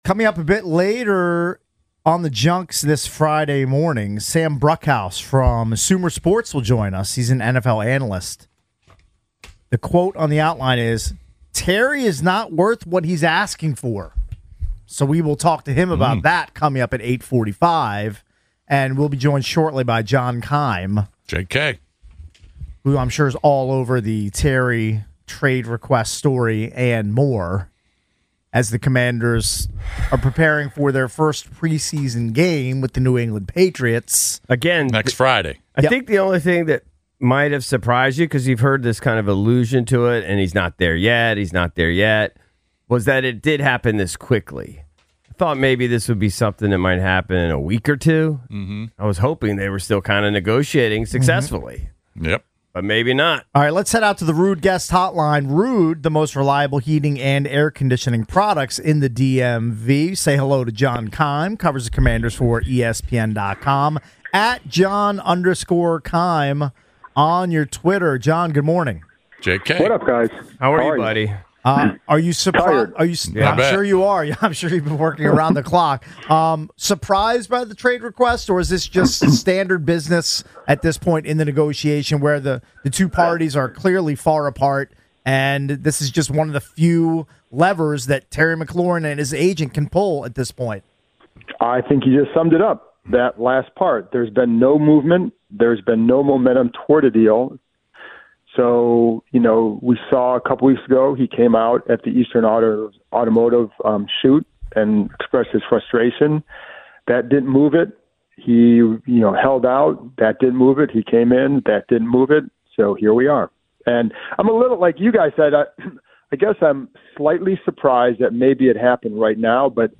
Callers Weigh In